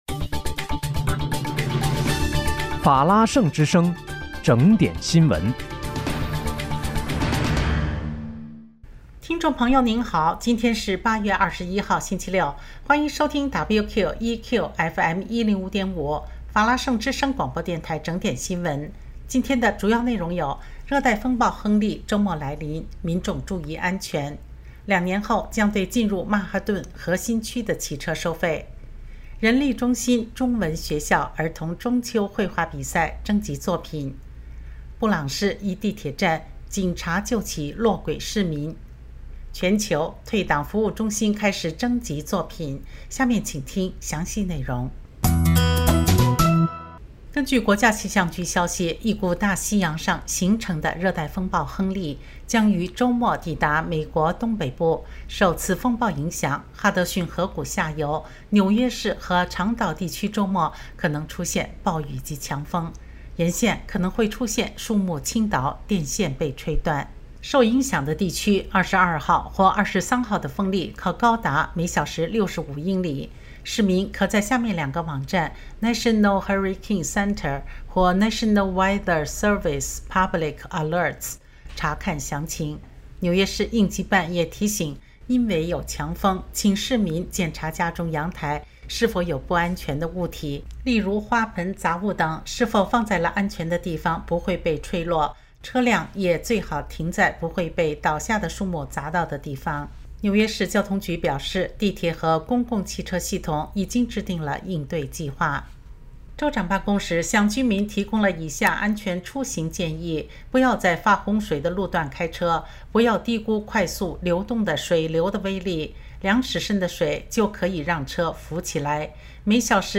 8月21日（星期六）纽约整点新闻
听众朋友您好！今天是8月21号，星期六，欢迎收听WQEQFM105.5法拉盛之声广播电台整点新闻。